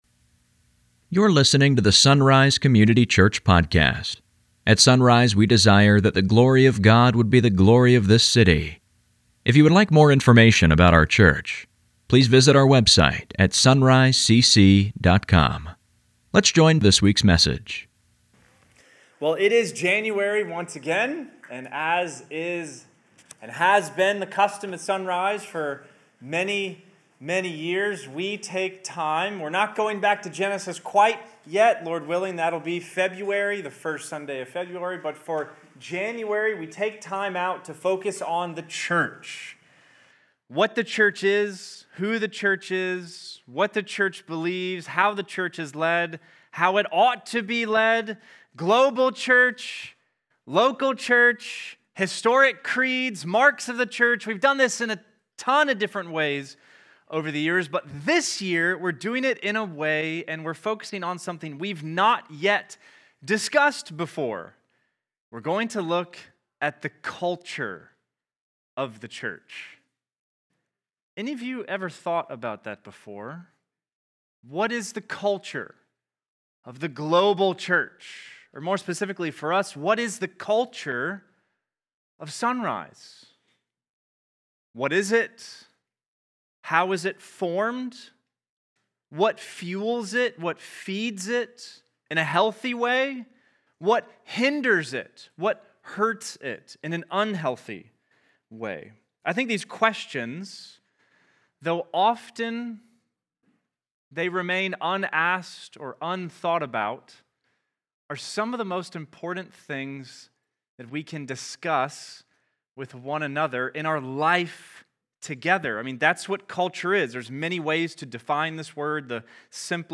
It is January once again, and as is our custom each year, we now turn to a sermon series on the Church.